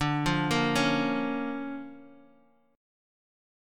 DmM7#5 Chord